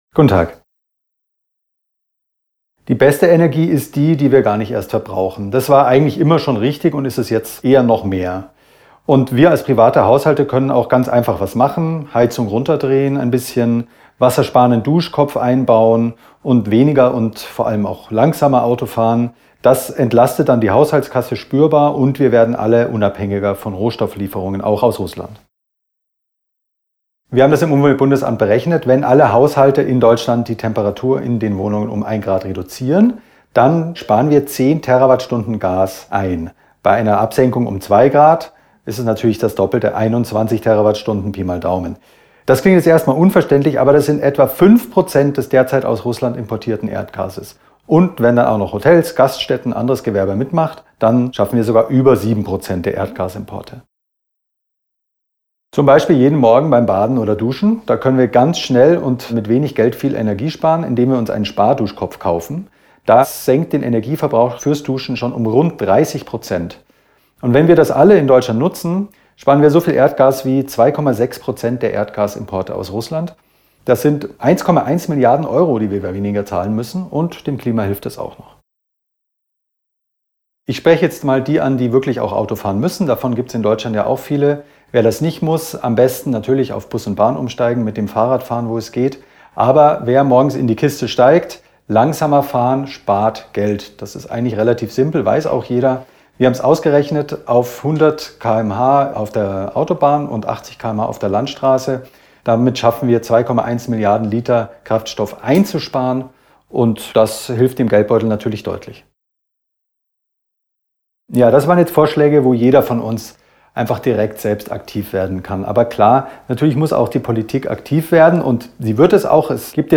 Interview: 2:26 Minuten